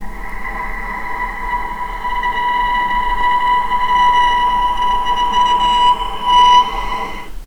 vc-B5-pp.AIF